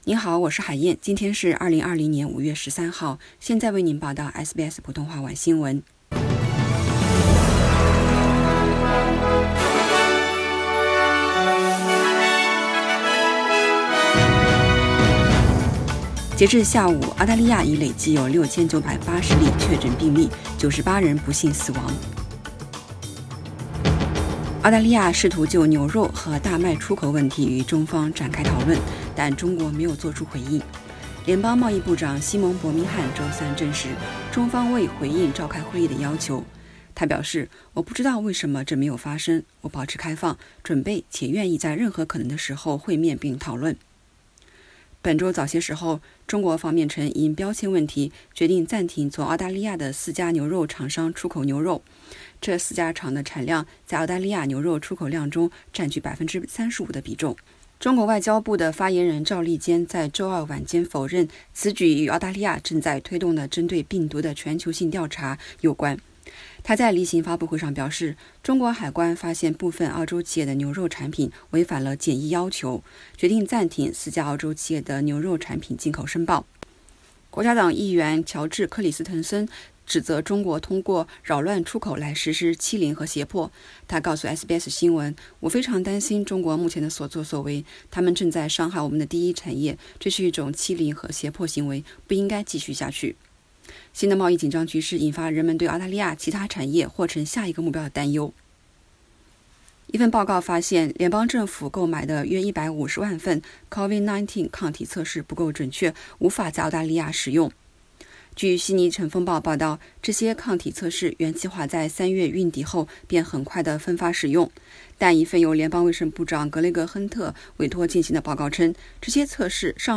SBS晚新闻（5月13日）